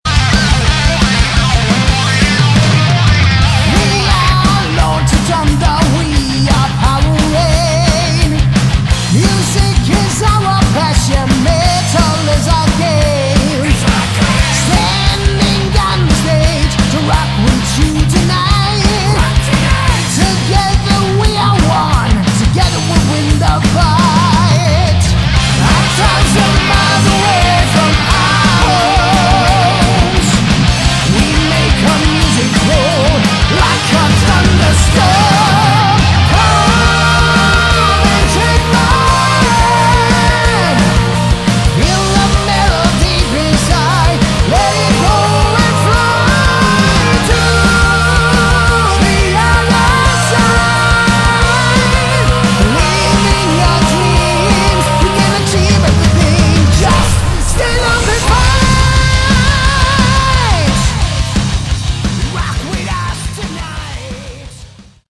Category: Melodic Metal
vocals
guitar
bass
drums